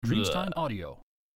Männliche Ekel-Stimme